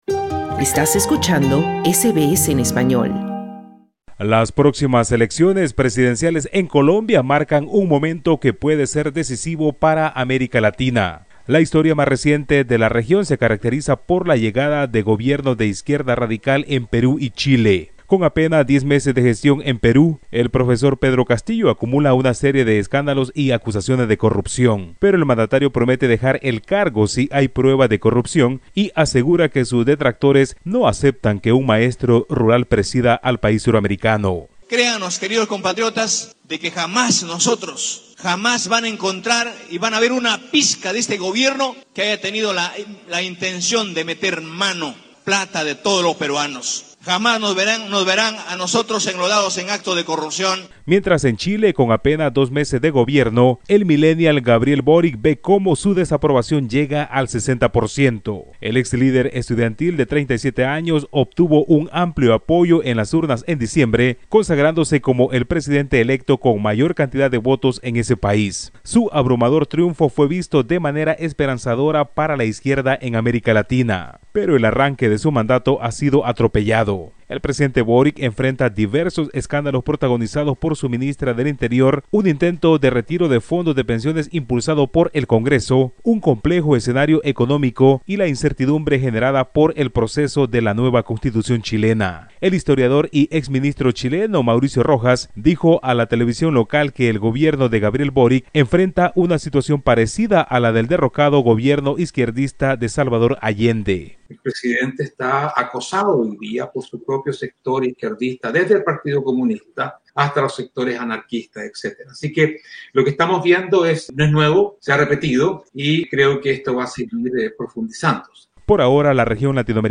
El próximo 29 de mayo se celebrarán unas elecciones presidenciales que podrían cambiar el rumbo conservador que ha mantenido el país en los últimos años. Escucha este informe